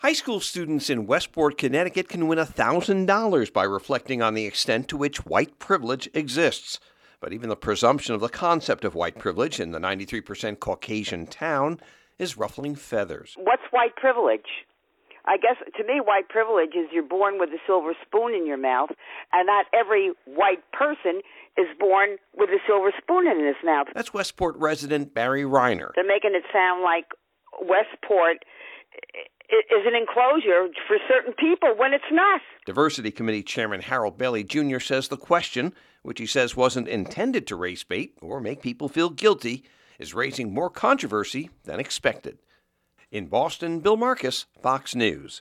REPORTS: